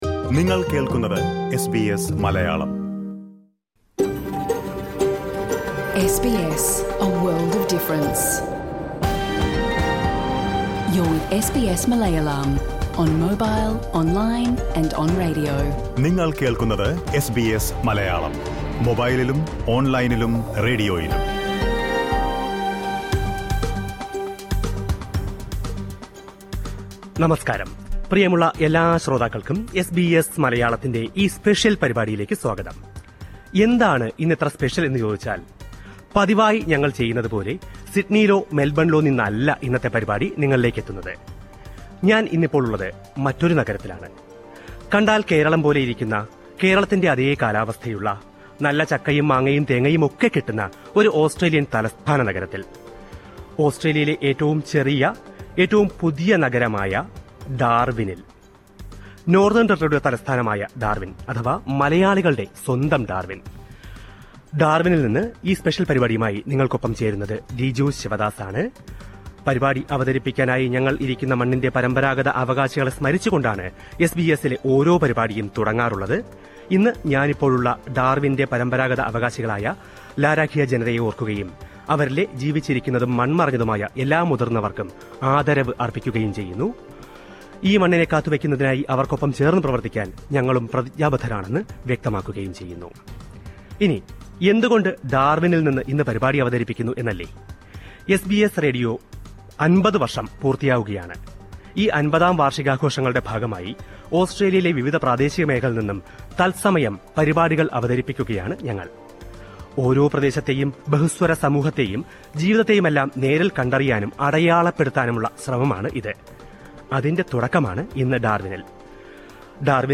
ഓസ്‌ട്രേലിയയിലെ ഏറ്റവും പുതിയ, ഏറ്റവും ചെറിയ തലസ്ഥാന നഗരമാണ് ഡാര്‍വിന്‍. കേരളത്തിലേതിന് സമാനമായ കാലാവസ്ഥയും, സസ്യജാലങ്ങളുമെല്ലാമുള്ള ഡാര്‍വിന്‍, മലയാളികള്‍ക്ക് ഓസ്‌ട്രേലിയന്‍ കുടിയേറ്റത്തിന് പല വഴികളും തുറക്കുന്നുമുണ്ട്. SBS റേഡിയോയുടെ 50ാം വാര്‍ഷികാഘോഷങ്ങളുടെ ഭാഗമായി ഡാര്‍വിനില്‍ നിന്ന് എസ് ബി എസ് മലയാളം നടത്തിയ പ്രത്യേക റേഡിയോ ഷോയുടെ പൂര്‍ണരൂപം ഇവിടെ കേള്‍ക്കാം.
SBS Malayalam's special live show from Darwin on 2025 May 22, Thursday.